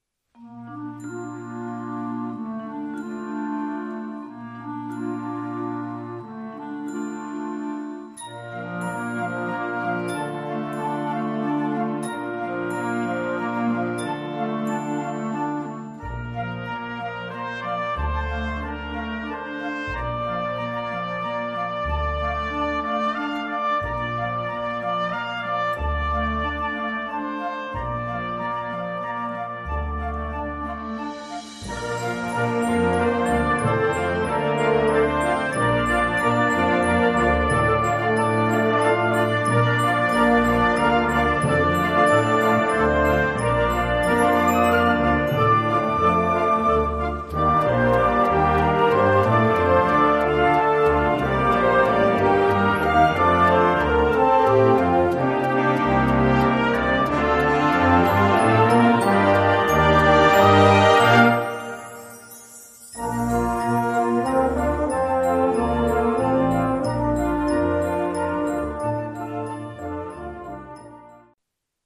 This is a concert band arrangement.